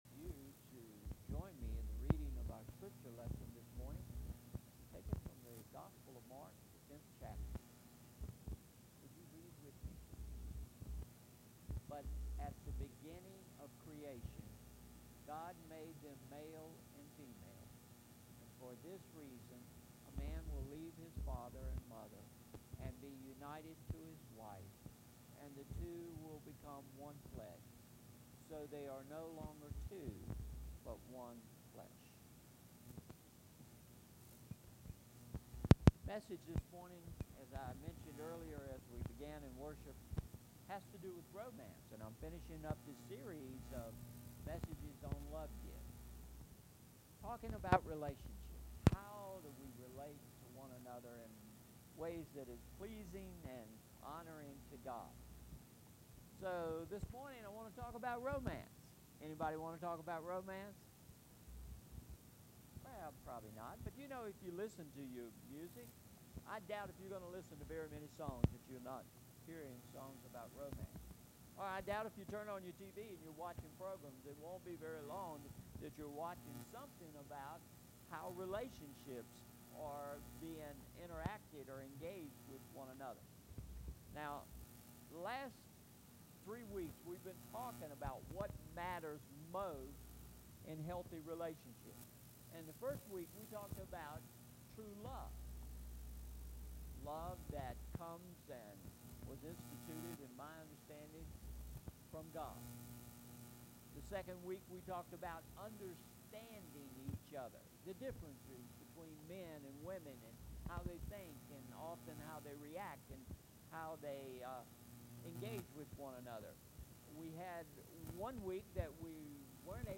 Sermon Series: Love Gifts: What Matters Most – Part 4